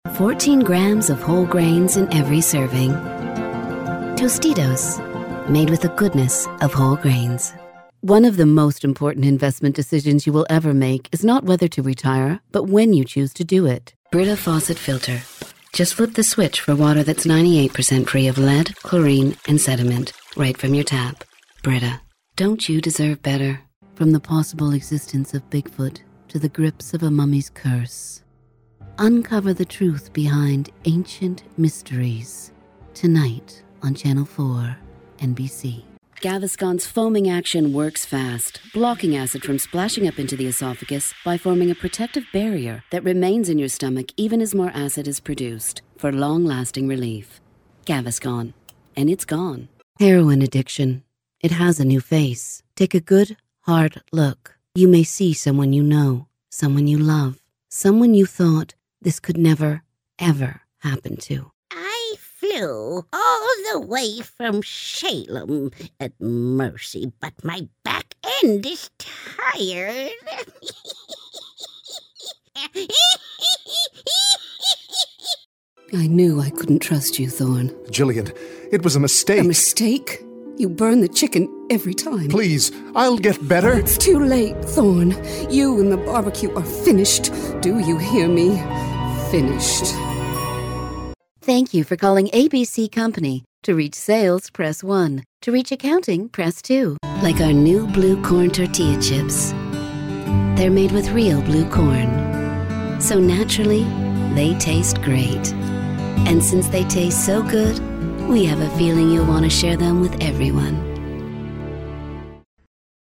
Unique female voice over professional, from sultry and smooth, to wry and conversational
Sprechprobe: Sonstiges (Muttersprache):
Unique Female Voice Talent